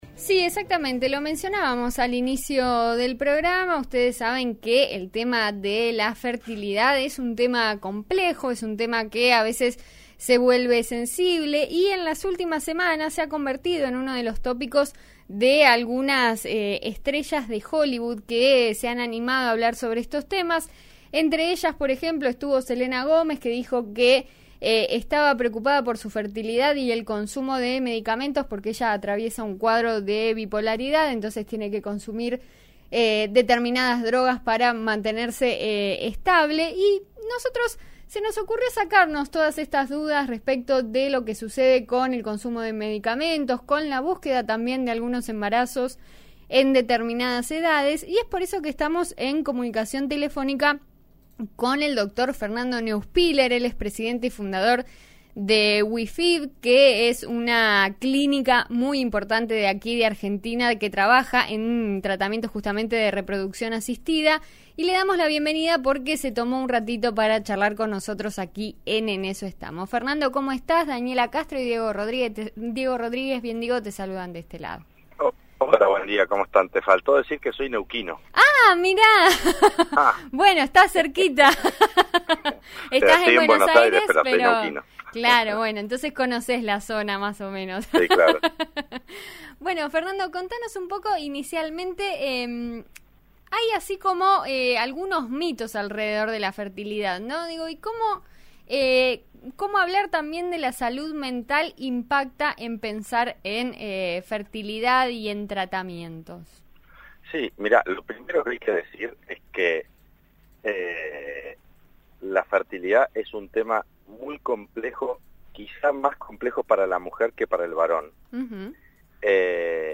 Luego de varios testimonios conocidos en Hollywood sobre el acceso y la posibilidad de la maternidad, 'En Eso Estamos' de RN RADIO dialogó con el doctor